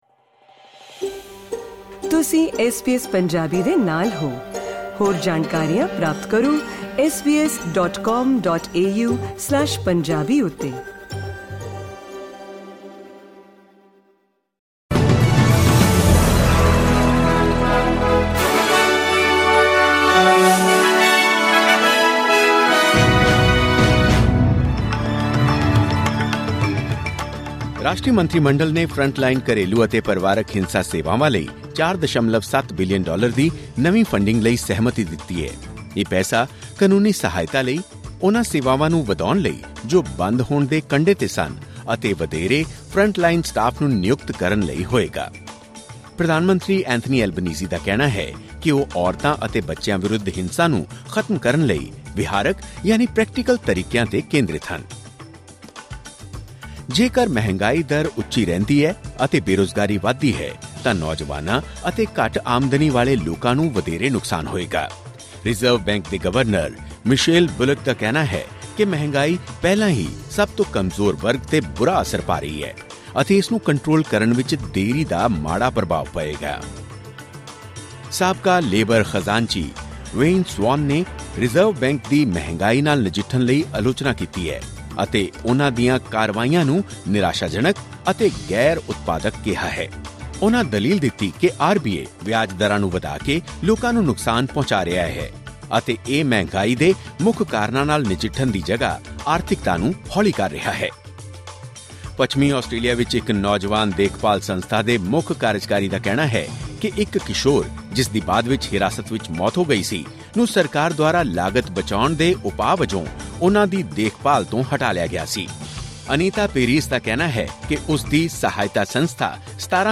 ਐਸ ਬੀ ਐਸ ਪੰਜਾਬੀ ਤੋਂ ਆਸਟ੍ਰੇਲੀਆ ਦੀਆਂ ਮੁੱਖ ਖ਼ਬਰਾਂ: 6 ਸਤੰਬਰ 2024